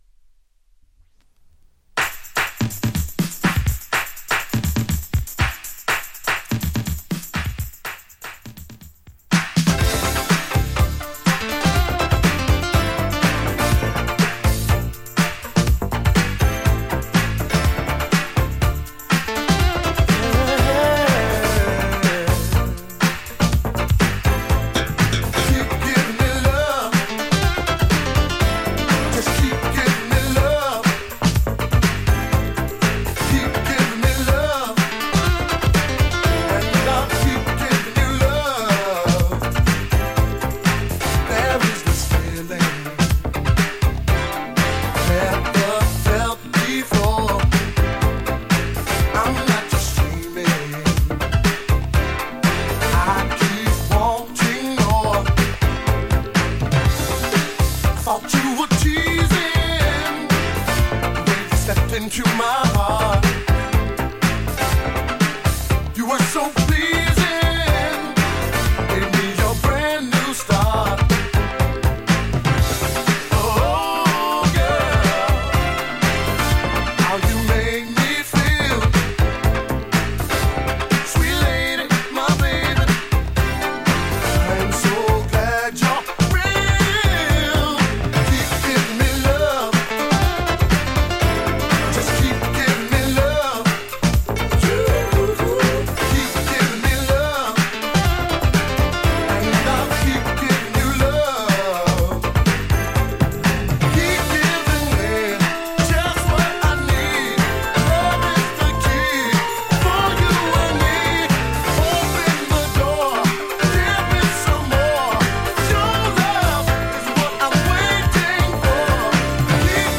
ジャンル(スタイル) FUNK / SOUL / DISCO / ELECTRONIC FUNK